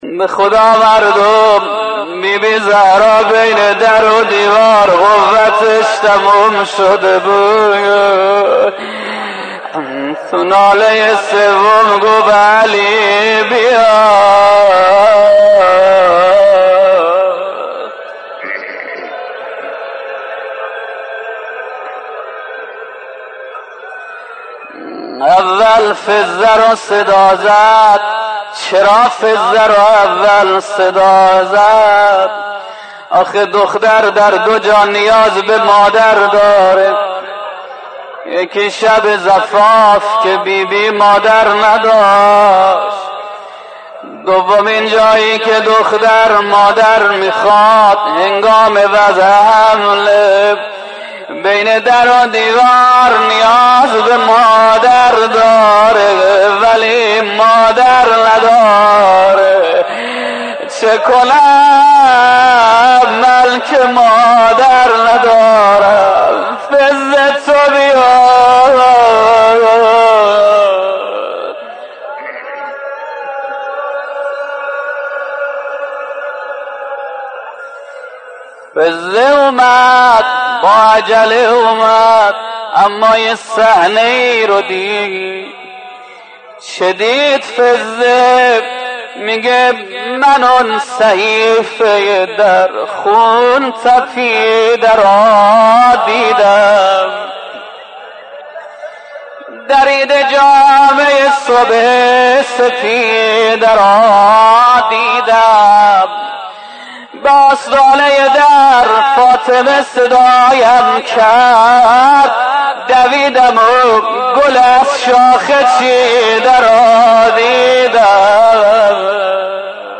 دانلود مداحی شهادت حضرت زهراع - دانلود ریمیکس و آهنگ جدید